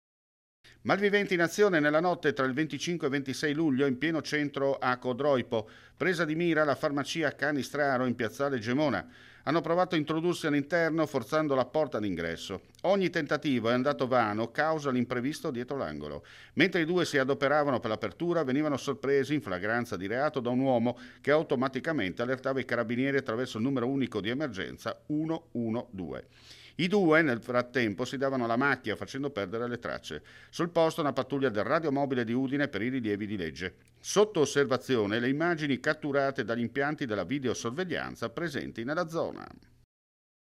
FRIULTV GIORNALE RADIO: LE ULTIME NOTIZIE DAL FRIULI VENEZIA GIULIA